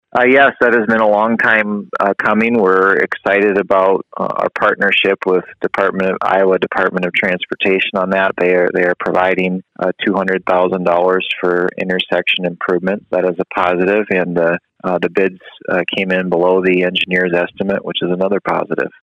Sheldon City Manager Sam Kooiker says the project has been a long time coming.